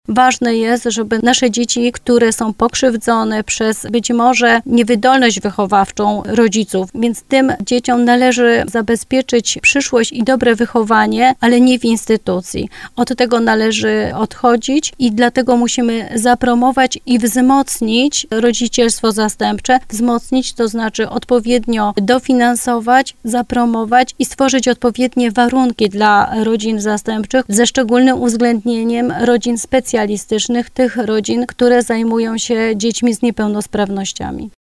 – Jedną z najważniejszych i najbardziej oczekiwanych zmian w pieczy zastępczej jest odejście od opieki instytucjonalnej. Chodzi o to, by dzieciom, które znajdują się w trudnej sytuacji, zapewnić prawdziwy dom – mówiła w programie Słowo za Słowo poseł PiS Urszula Rusecka.